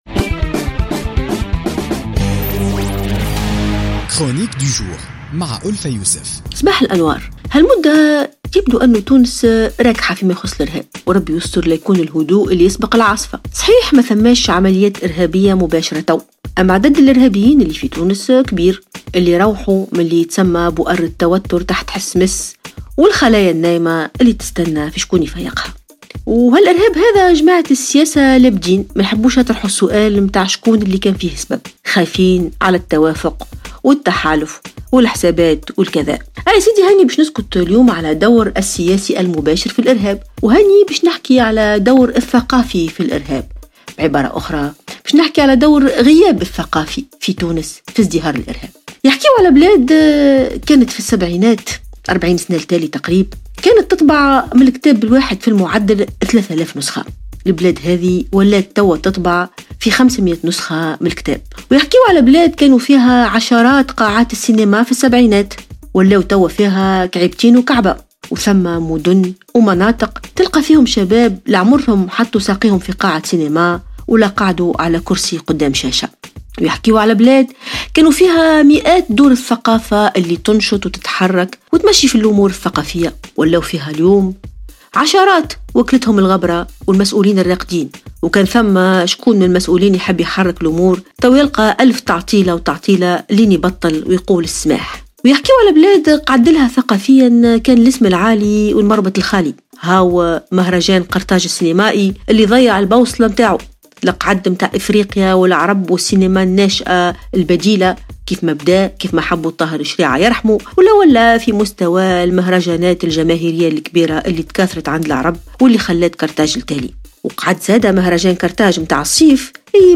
تطرقت الكاتبة ألفة يوسف في افتتاحية اليوم الجمعة 17 فيفري 2017 إلى حالة الهدوء التي تعيشها تونس في مايخص الإرهاب معبرة عن خشيتها من أن يكون الهدوء الذي يسبق العاصفة حسب قولها.